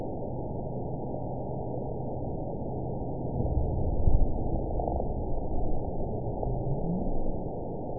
event 920079 date 02/22/24 time 01:09:21 GMT (1 year, 2 months ago) score 9.33 location TSS-AB03 detected by nrw target species NRW annotations +NRW Spectrogram: Frequency (kHz) vs. Time (s) audio not available .wav